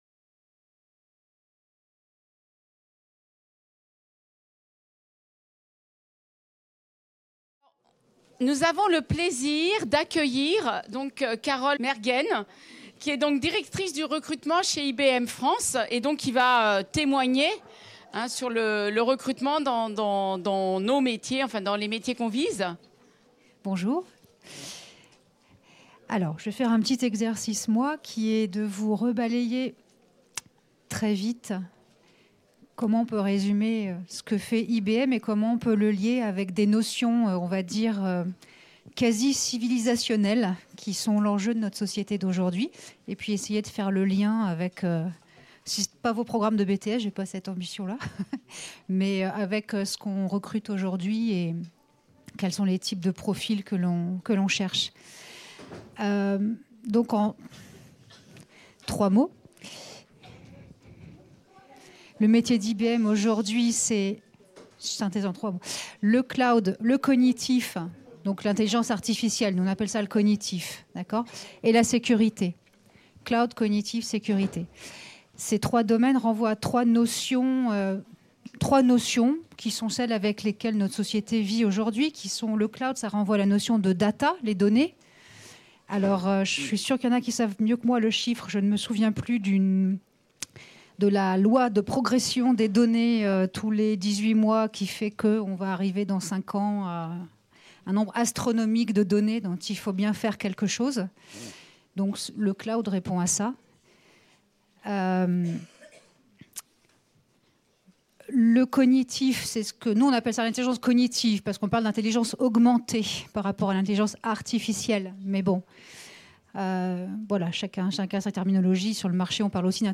Rénovation du BTS SAM - 5 - Témoignage sur le recrutement | Canal U